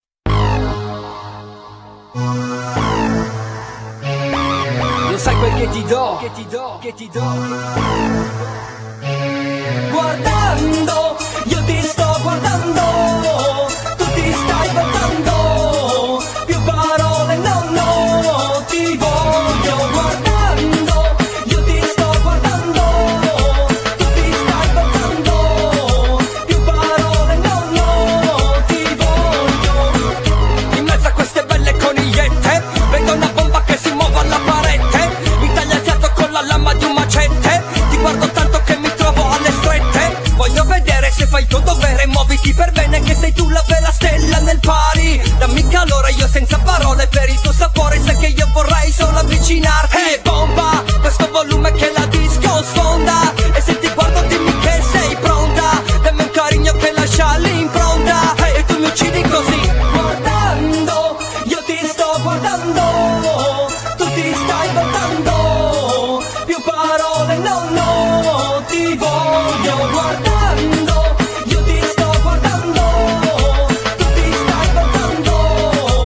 Genere : Raggaeton